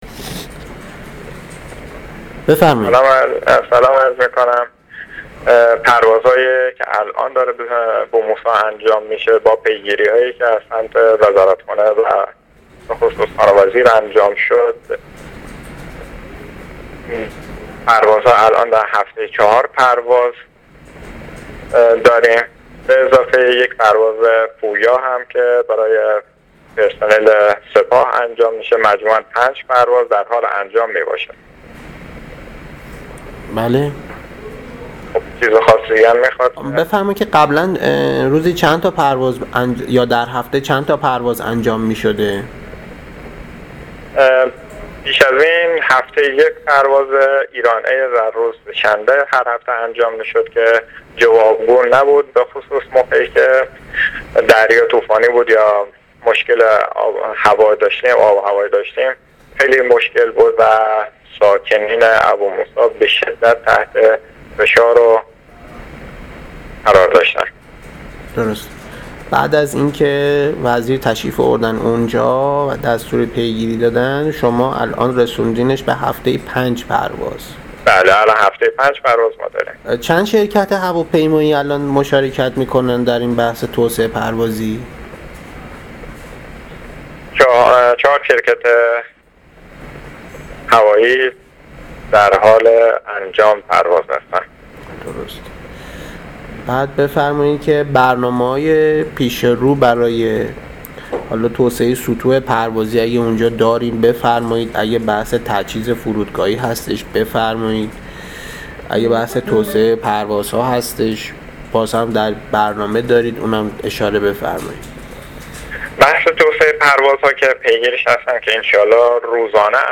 گزارش رادیو اینترنتی از آخرین وضعیت ترافیکی جاده‌ها تا ساعت ۱۳ پنجم آذر؛